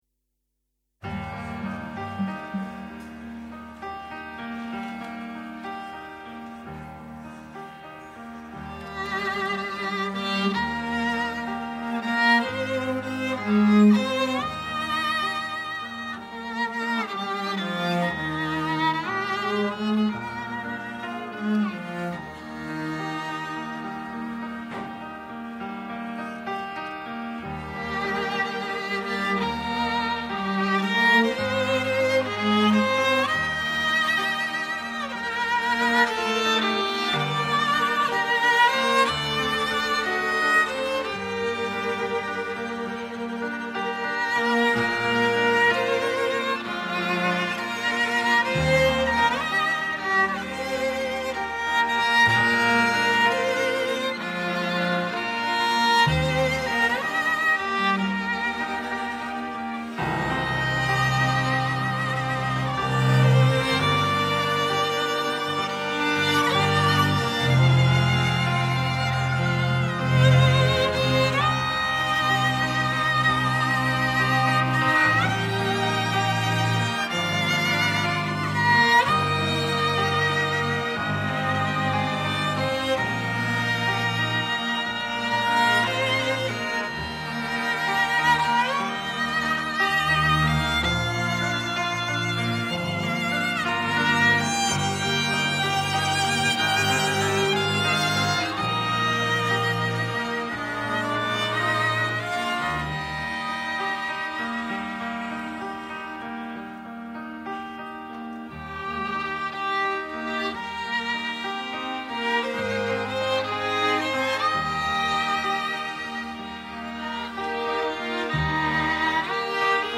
26 November 2023 || SG Kerssangdiens